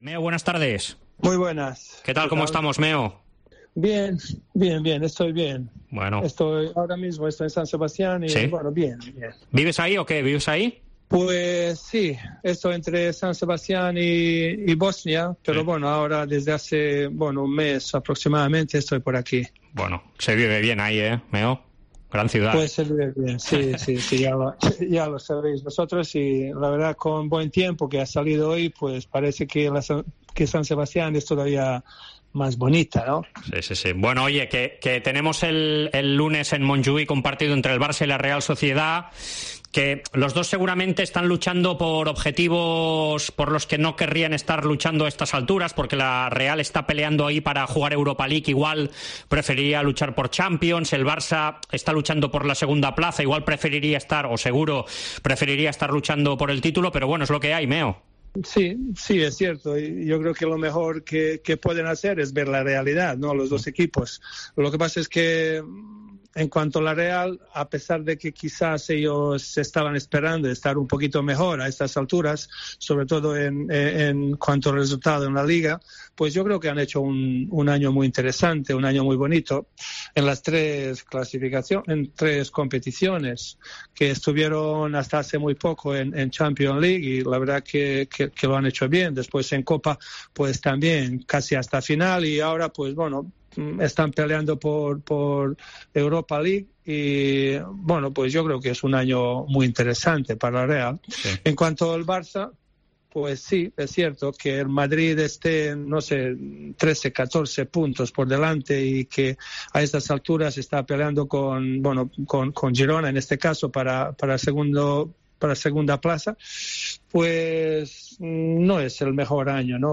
AUDIO: El histórico delantero bosnio atiende a Esports COPE para analizar el próximo partido de Liga entre el Barcelona y la Real Sociedad.